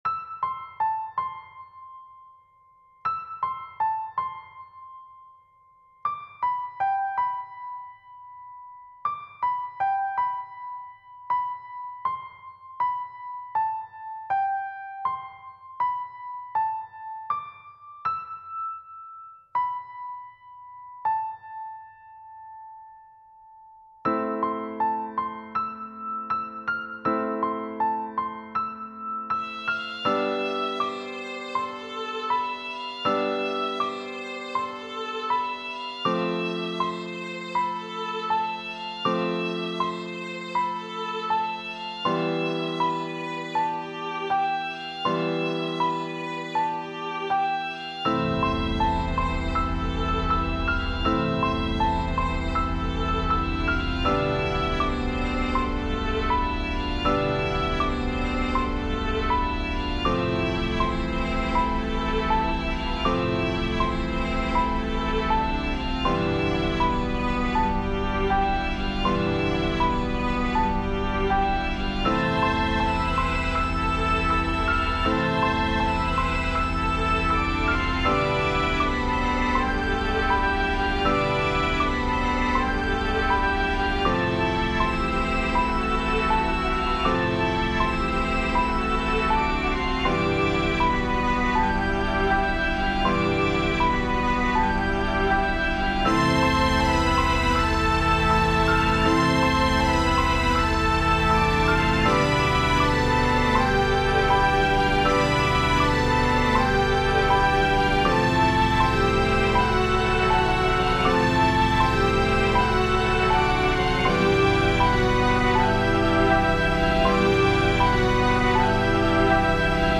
posted a year ago Storyteller and Future Novelist An emotional song about the solemn question of what can happen in one's life.
Music / Classical
song music emotional classical humanity human_nature sad dark sorrow